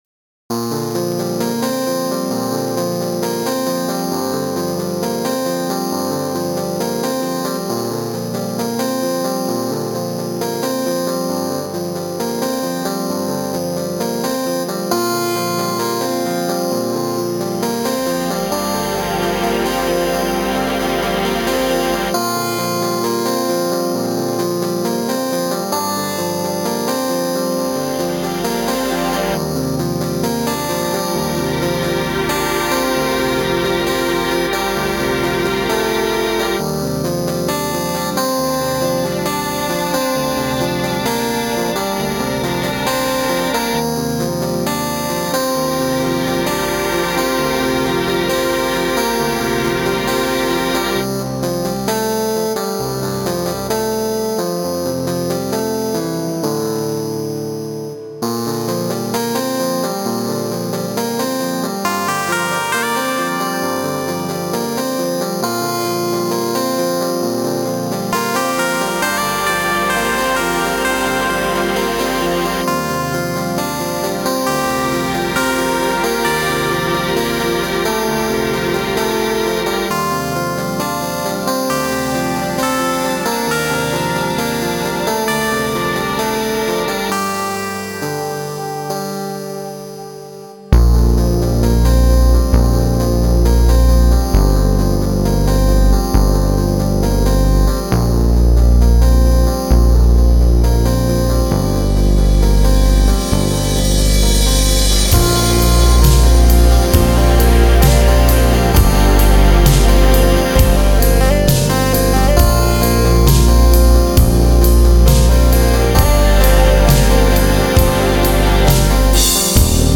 3) "Warm Shelter" - I really love the composition and the arrangement of the instruments is spot-on. Those flowing arps, the gentle swell of the strings and pads overall gives a warm feeling.
I didn't hate it, but I wasn't quite sure why things were distorted?